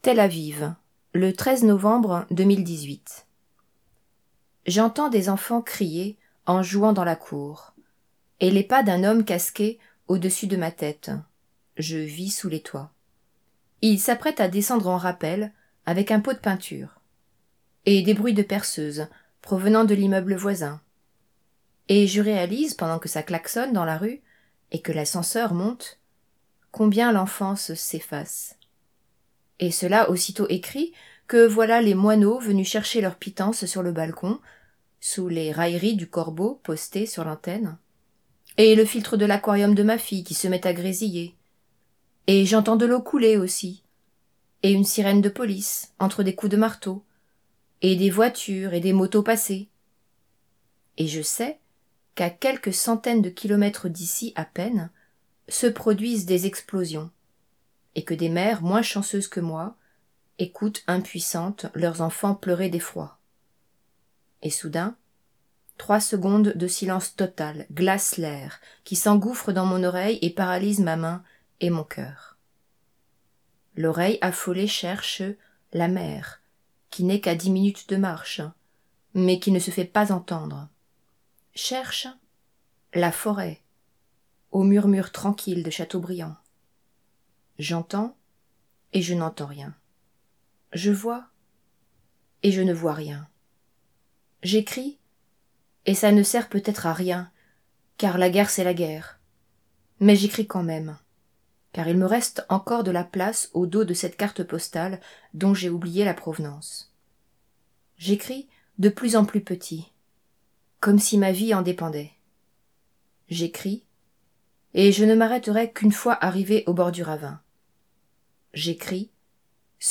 Carte postale reçue au bureau de L'aiR Nu